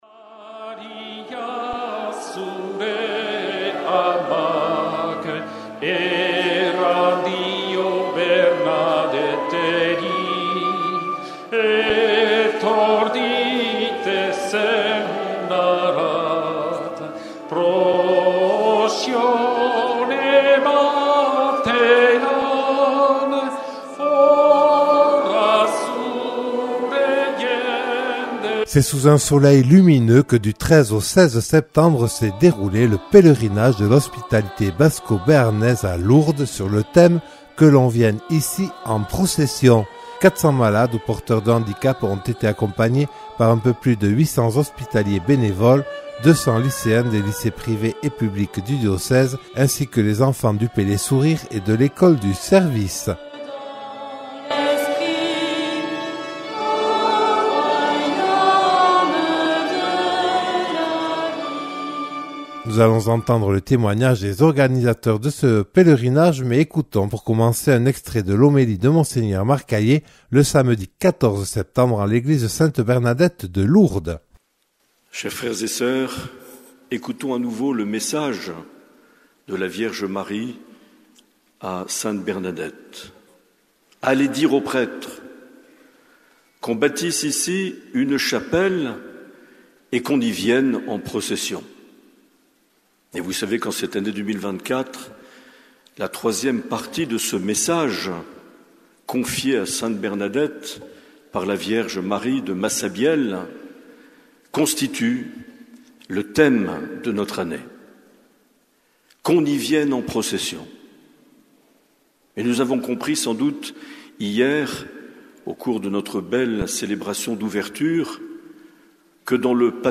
Premier reportage : bénédiction du cierge de l’HBB à la chapelle des lumières